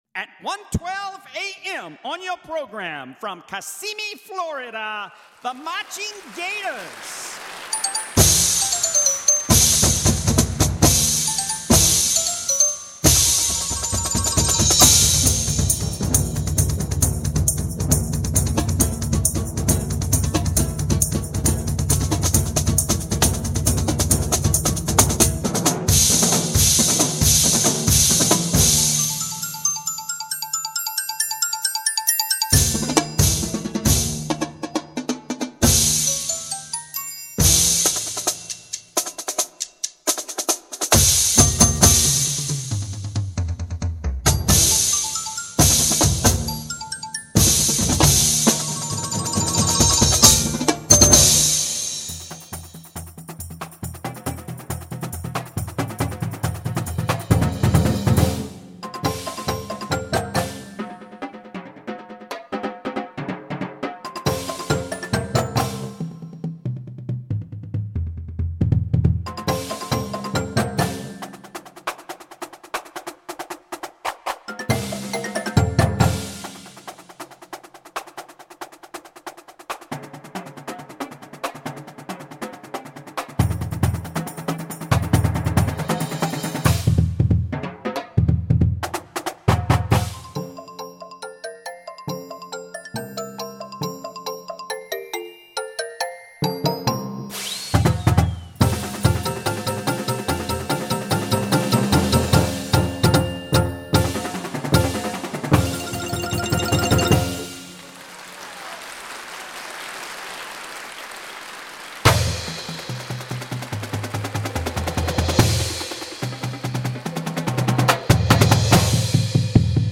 Voicing: dr line